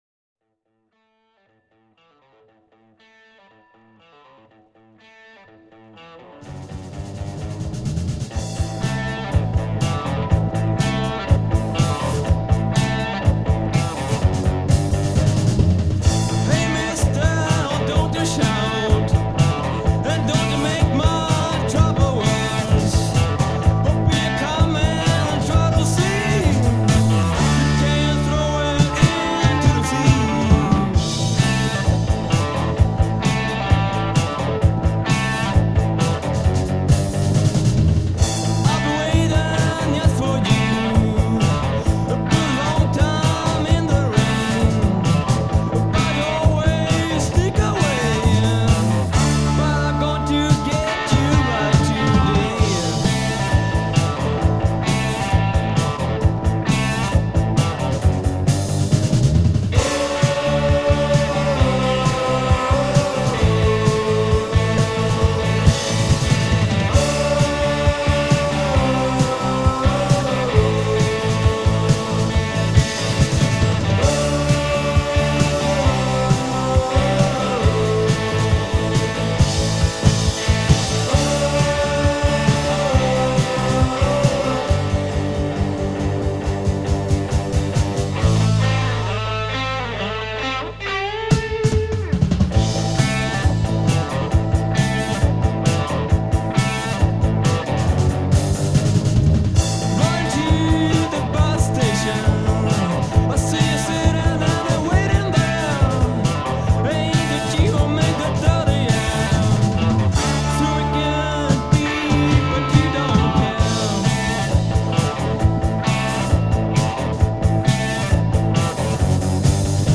March 13-14, 1982 (From pure old vinyl record)